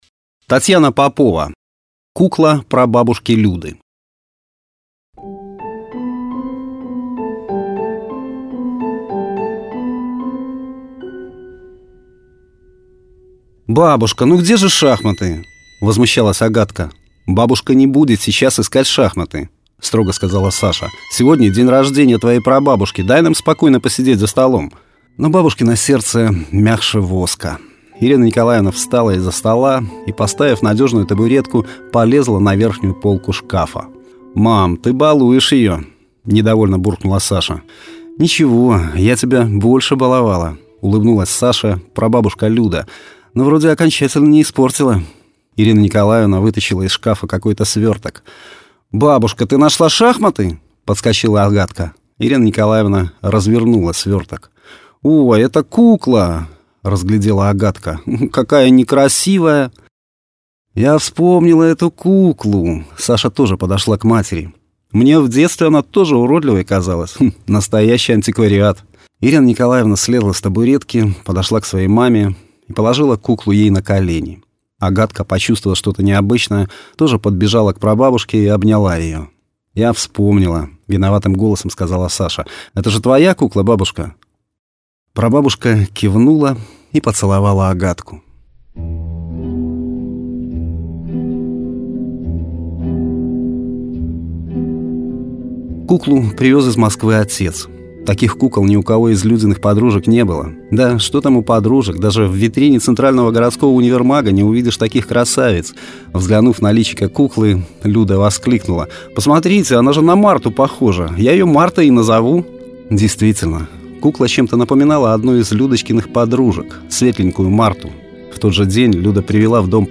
Аудиокниги
Качество: mp3, 256 kbps, 44100 kHz, Stereo